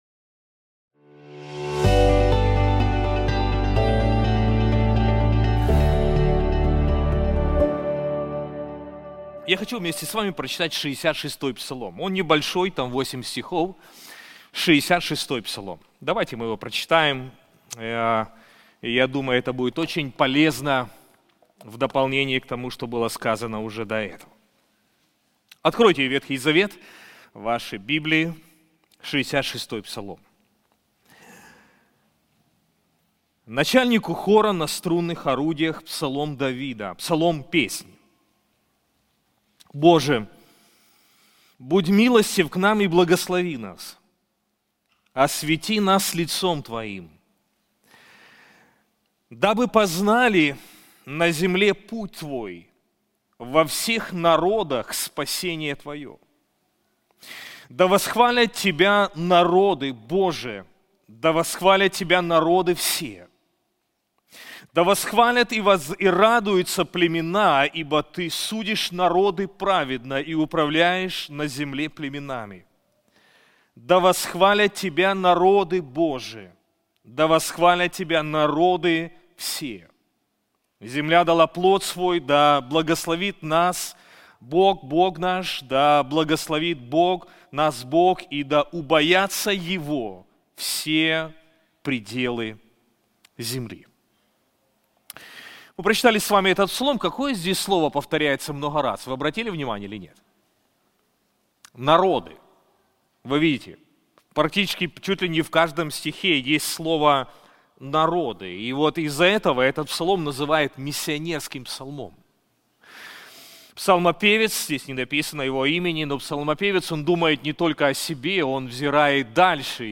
В этой проповеди мы вместе разберём 66-й псалом, который часто называют миссионерским из-за повторяющегося призыва к народам восхвалять Бога.